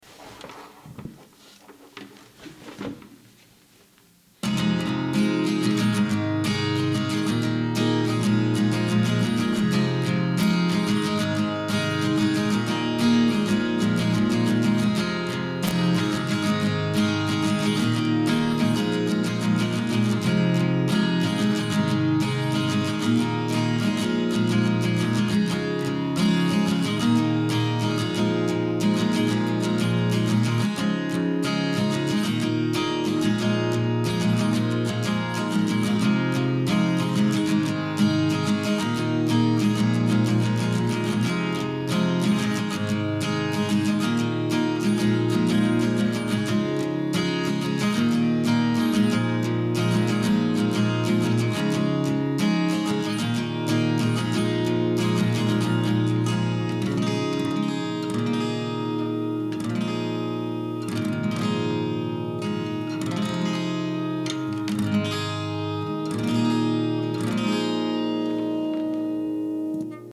Voici un samples de ma Cort en double tracking + reverb :